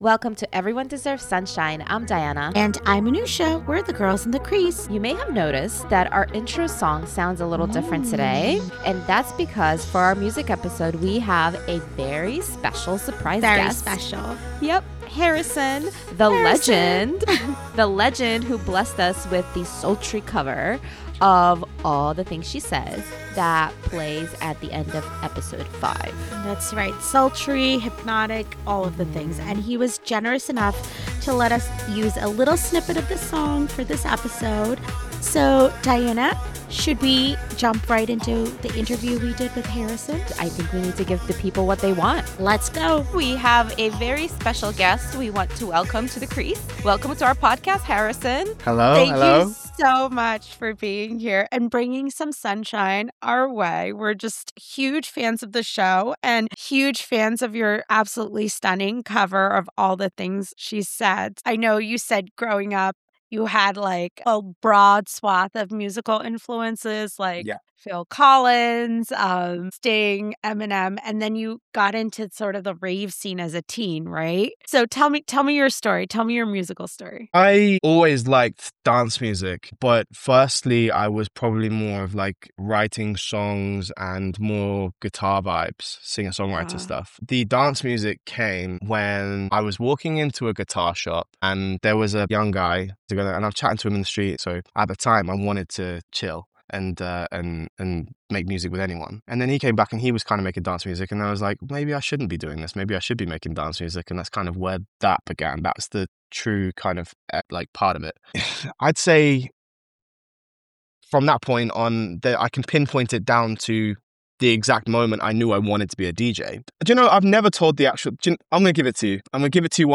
A Heated Rivalry Podcast